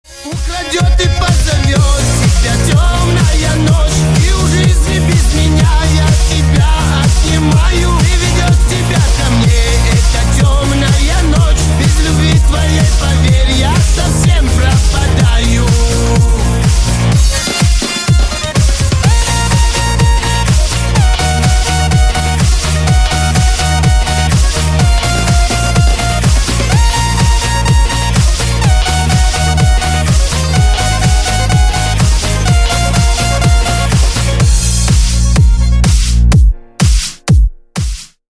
Ремикс # Поп Музыка # кавказские